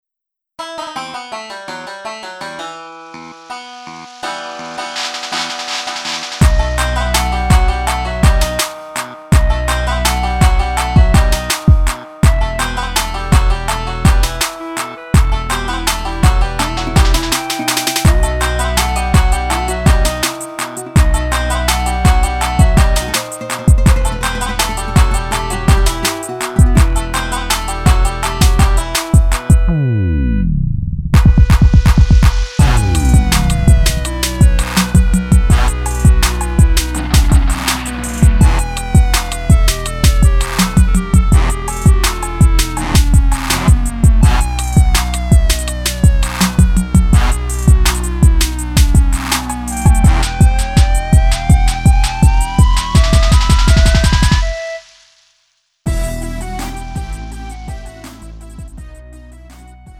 음정 원키 3:01
장르 가요 구분 Lite MR